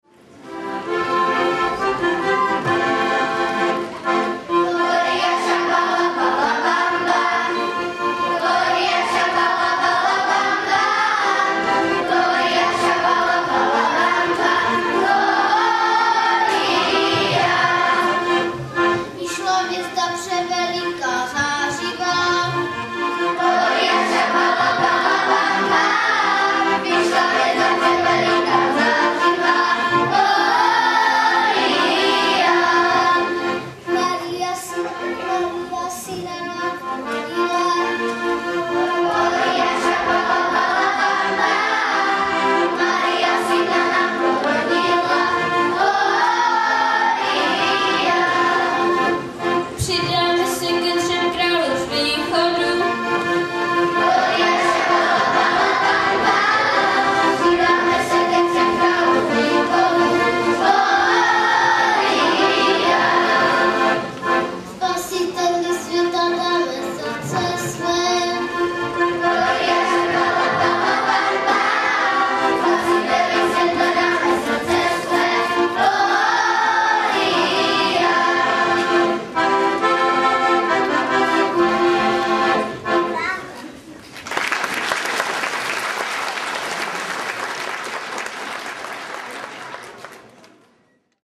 VÁNOČNÍ AKADEMIE ZŠ BÁNOV
Bánov … sál školy ... neděle 16.12.2007